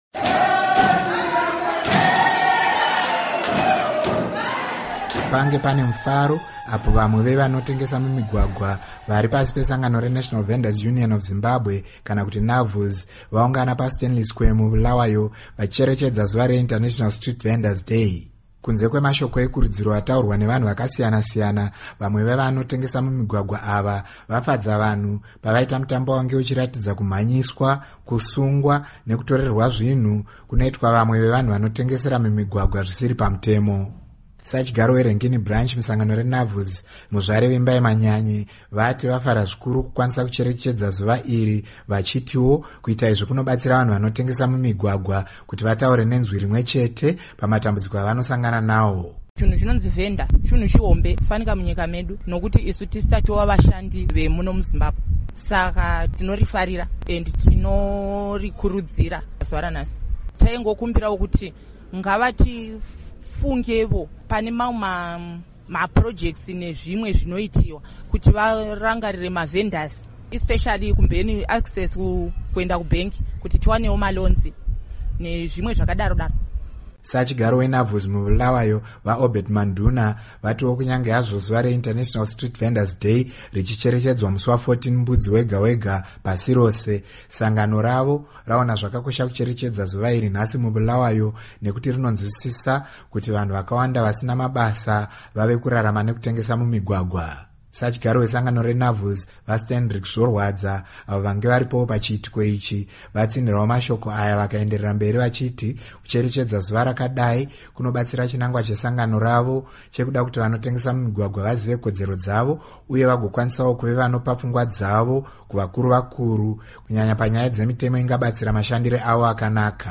Pange pane mufaro apo vamwe vevanotengesa mumigwagwa vari pasi pesangano reNational Vendors Union of Zimbabwe, Navuz, vaungana paStanley Square muBulawayo vachicherechedza zuva reInternational Day for Street Vendors.